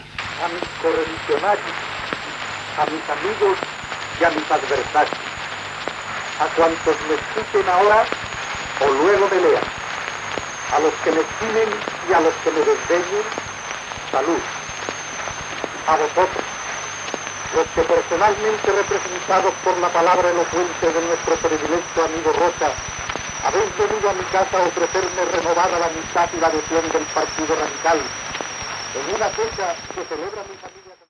Fragment del discurs d'Alejandro Lerroux, a Madrid, a l'homenatge rebut dels militants del Partido Radical, del qual n'era el cap, amb motiu de complir 69 anys.
Informatiu